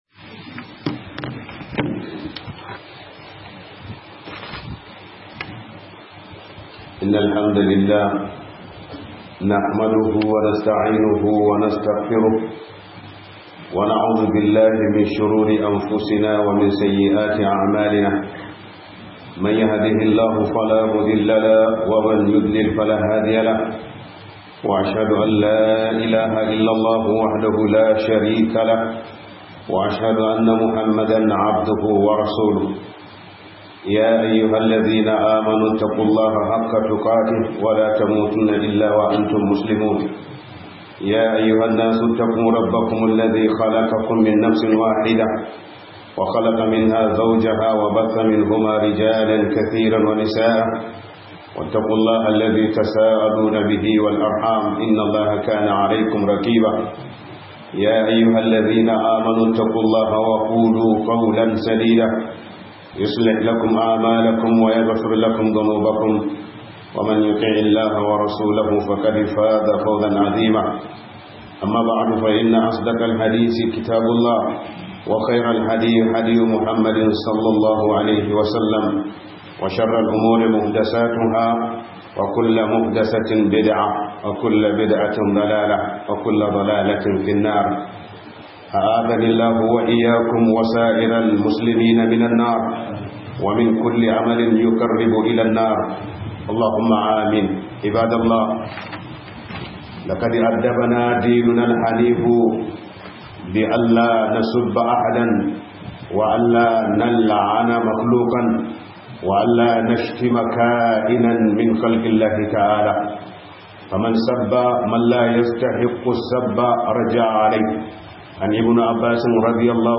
La'ana ba dabi'ar mumini bane - 2025-11-14 - HUDUBA